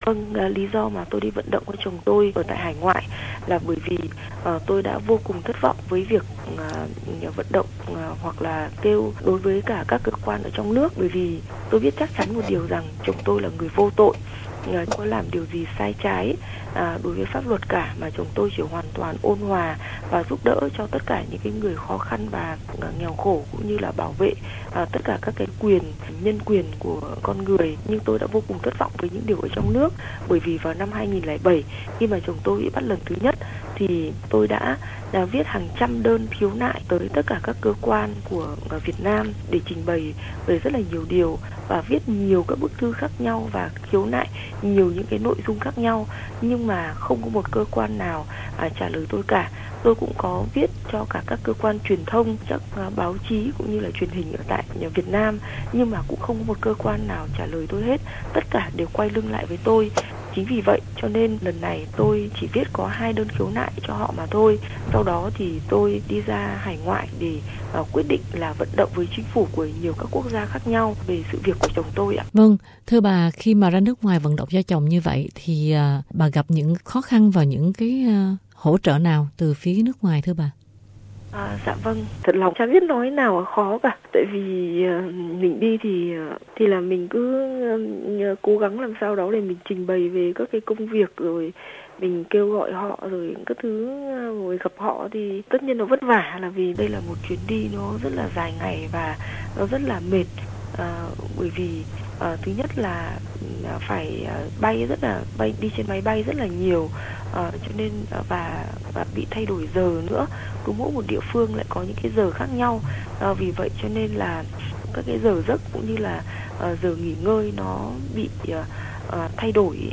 Trả lời phỏng vấn